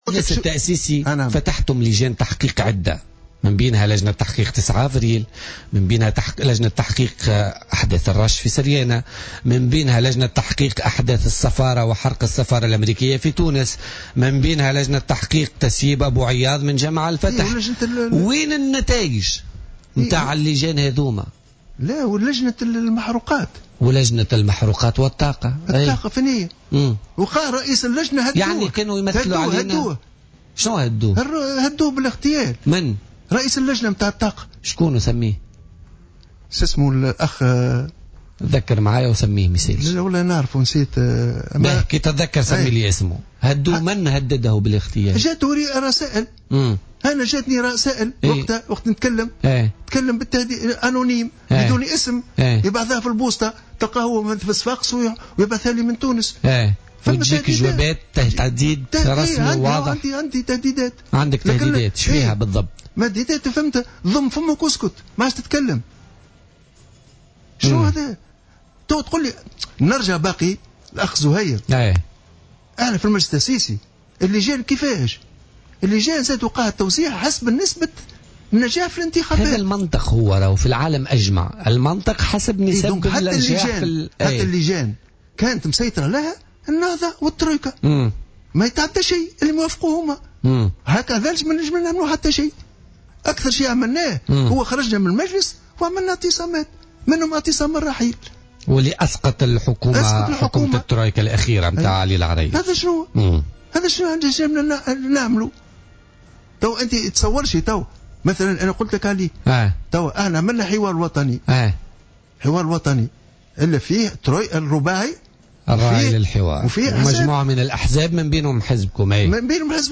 قال صالح شعيب، رئيس حزب "الخيار الثالث" ضيف برنامج "بوليتيكا" اليوم الأربعاء، إن معظم لجان التحقيق التي تم إحداثها صلب المجلس الوطني التأسيسي فشلت في الوصول إلى أية نتائج بخصوص الملفات التي تعهدت بها بسبب سيطرة حركة النهضة والترويكا على أشغالها، بحسب قوله.